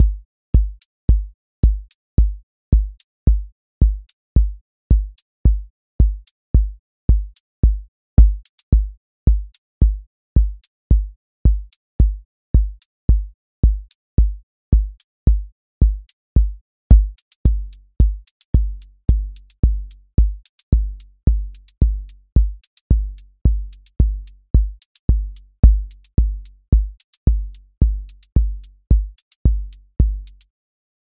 Four Floor Drive QA Listening Test house Template: four_on_floor April 18, 2026 ← Back to all listening tests Audio Four Floor Drive Open MP3 directly Selected Components macro_house_four_on_floor voice_kick_808 voice_hat_rimshot voice_sub_pulse Test Notes What This Test Is Four Floor Drive Selected Components macro_house_four_on_floor voice_kick_808 voice_hat_rimshot voice_sub_pulse